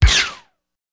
Generic Lasers
Laser Blast Quick